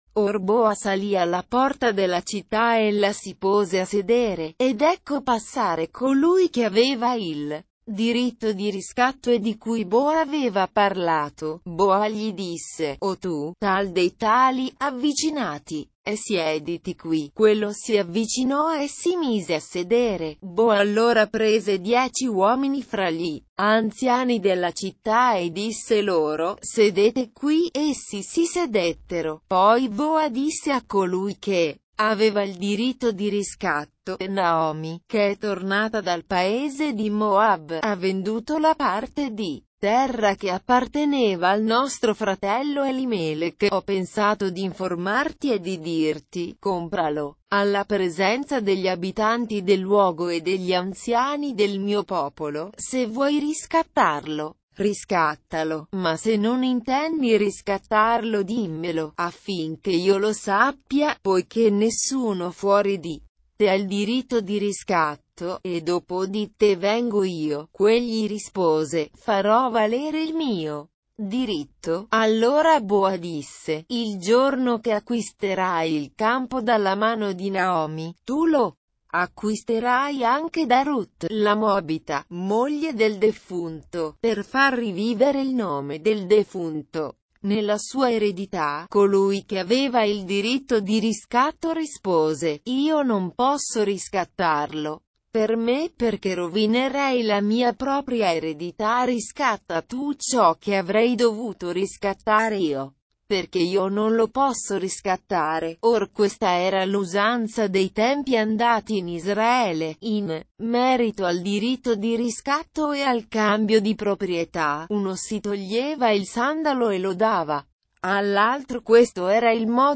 Leitura na versão LND - Italiano